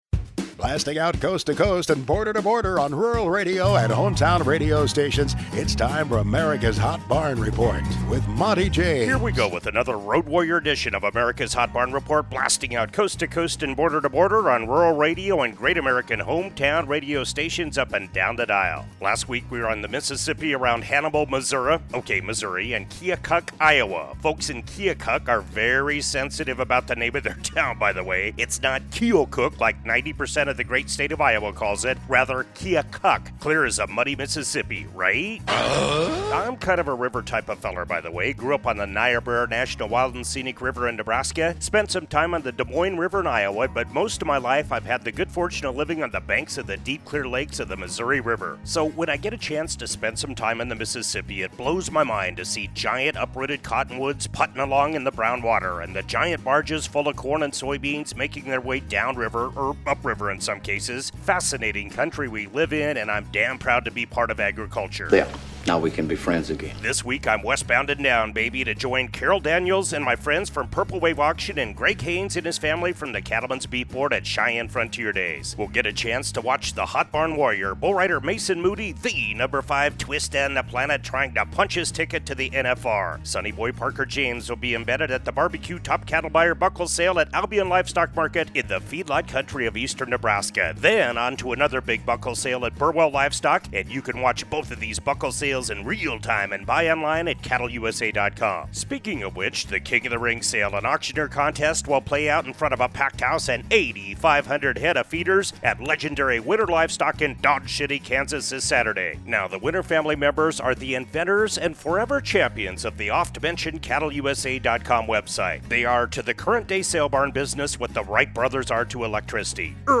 The Hot Barn Report features interviews with industry leaders, market analysts, producers and ranchers and features True Price Discovery from salebarns in Great Northern Beef Belt and across the nation.